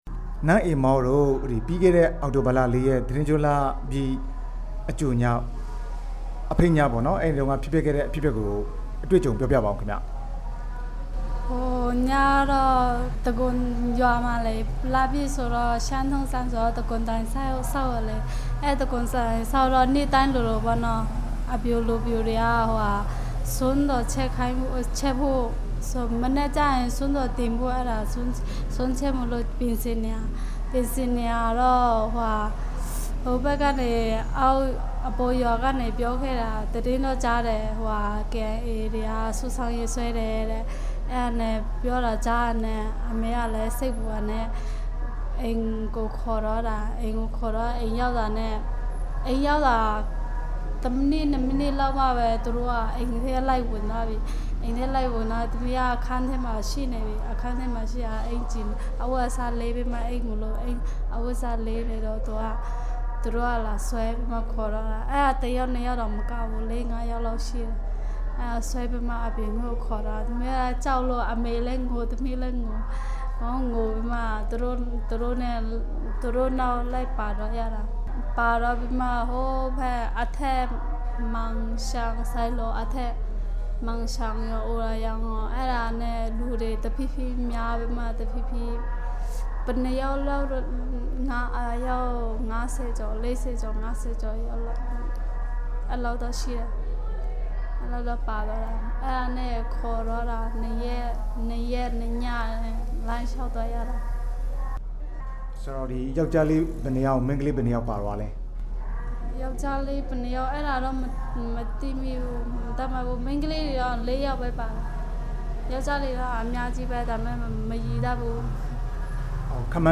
ကေအိုင်အေကနေ ပြန်လွတ်လာတဲ့ ရှမ်းအမျိုးသမီးနဲ့ မေးမြန်းချက်